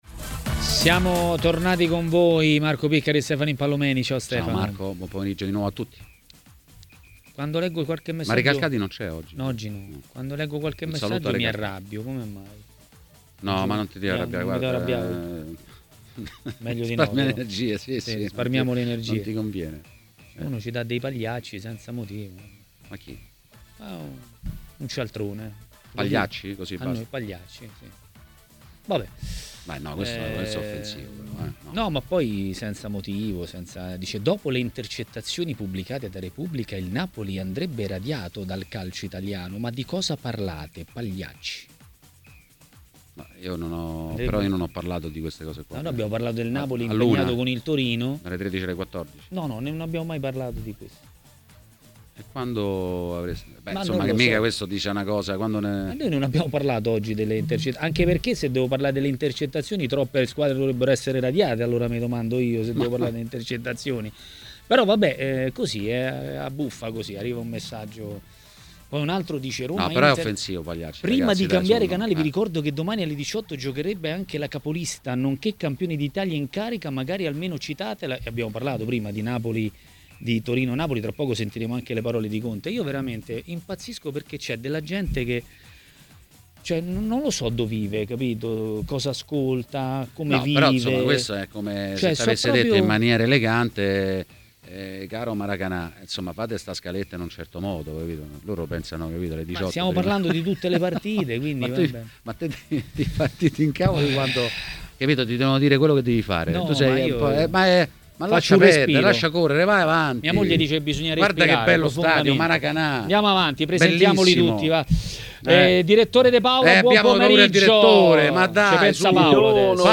A Maracanà, nel pomeriggio di TMW Radio, scatta l'ora dell'ex portiere Simone Braglia.